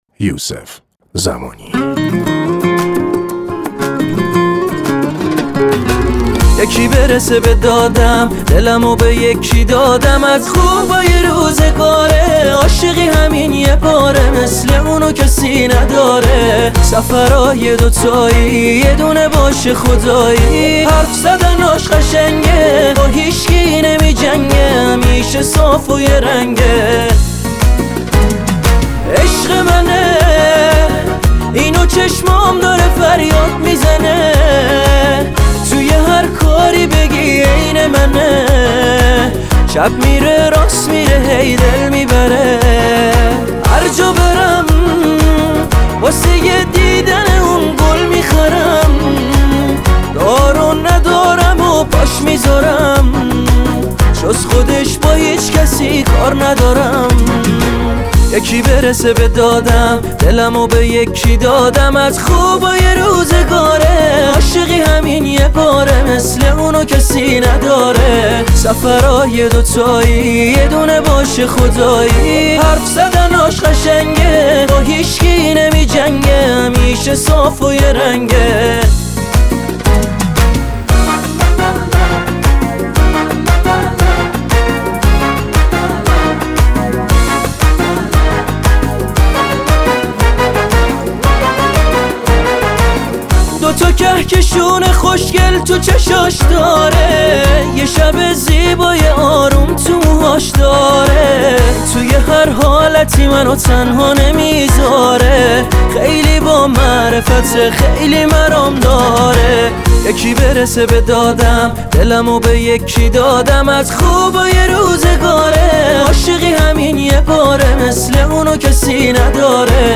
آهنگ شاد جدید